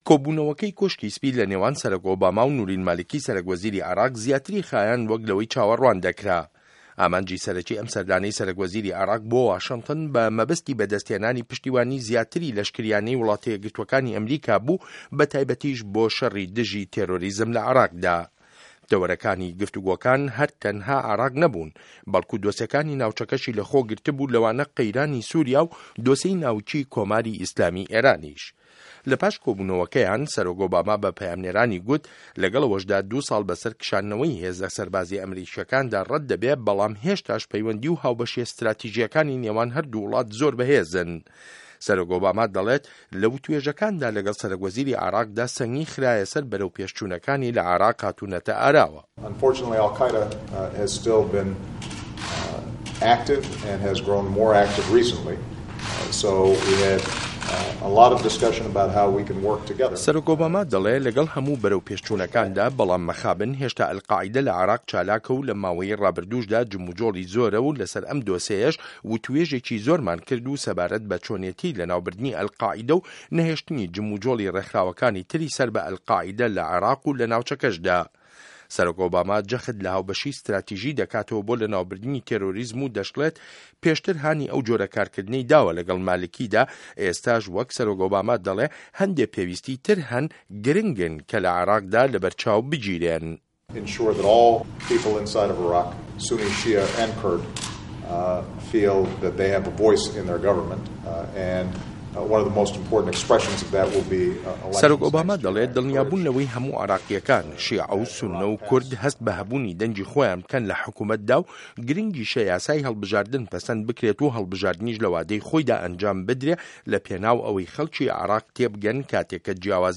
ڕاپۆرتی مالیکی له‌ کۆشکی سپی